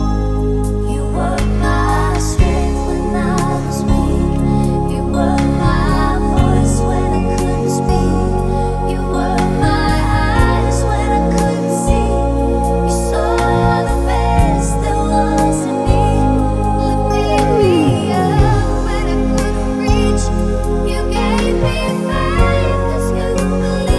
No Backing Vocals Pop (1990s) 4:41 Buy £1.50